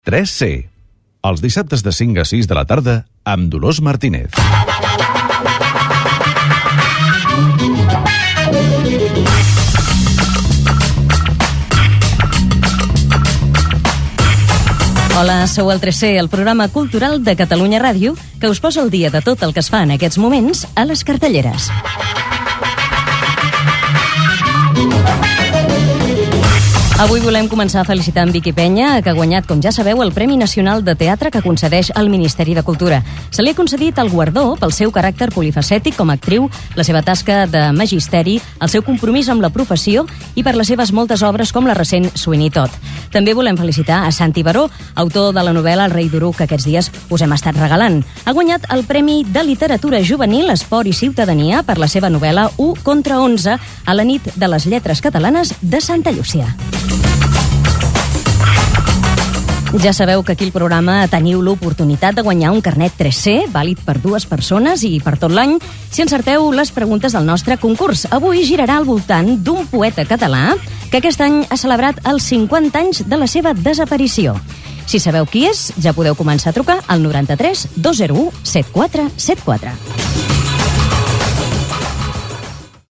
TRESC - Programa cultural a Catalunya Ràdio